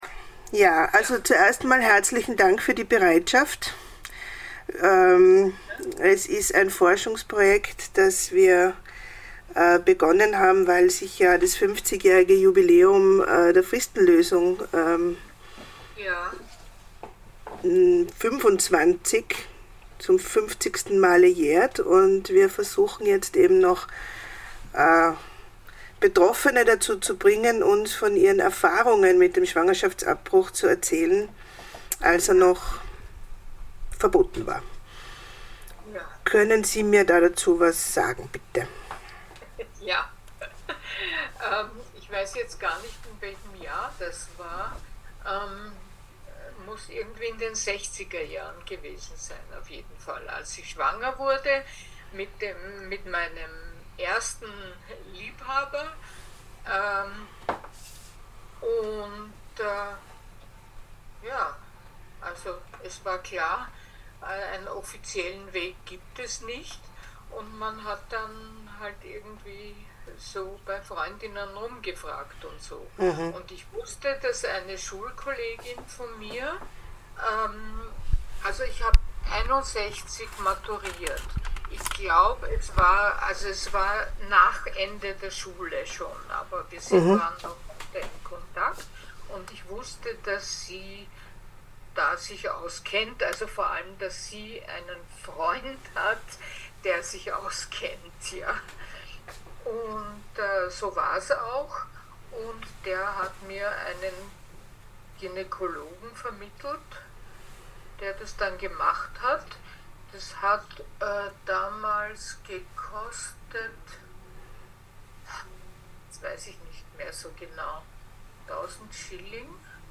Interview mit Erica Fischer. Das Interview wurde am 15.12.2022 per Zoom geführt.